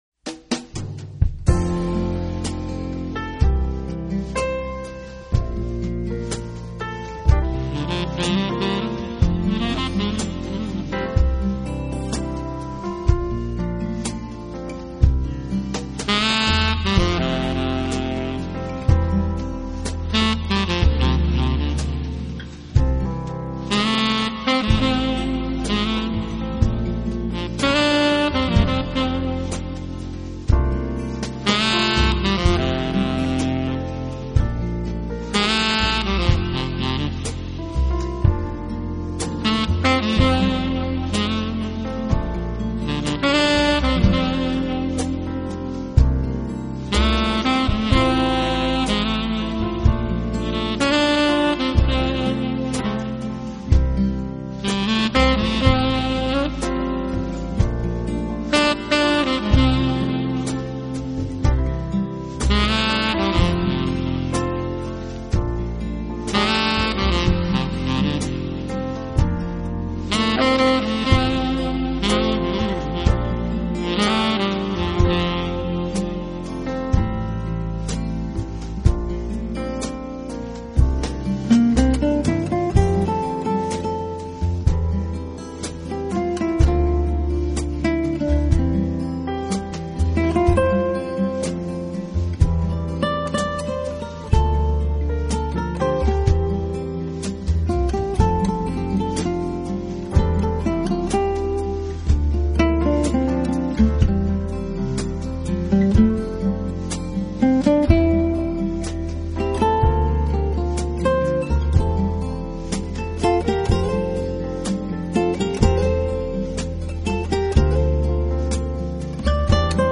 搭配上贝斯、钢 琴、鼓与吉他等乐器，以五重奏型式交相协奏著